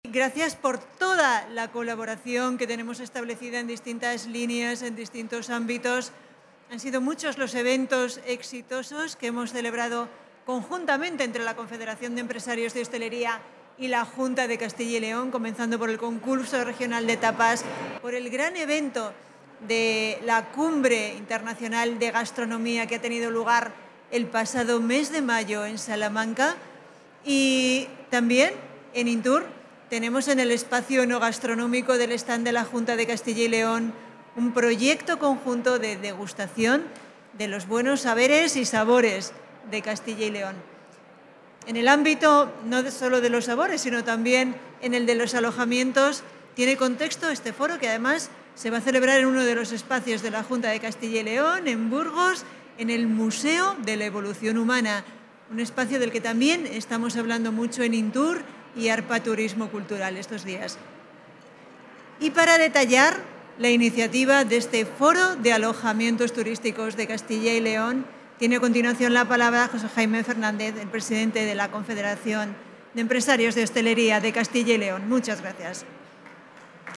Intervención de la viceconsejera.
La viceconsejera de Acción Cultural, Mar Sancho, ha participado en la presentación del Foro de Alojamientos Turísticos de Castilla y León que ha tenido lugar esta mañana en el marco de la Feria Internacional de Turismo de Interior, INTUR, que se celebra conjuntamente con AR-PA Turismo Cultural, y que abre desde hoy y hasta mañana, sus puertas a la visita para el público.